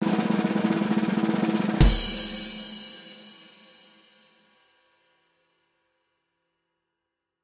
Звуки ожидания
Ожидание барабанов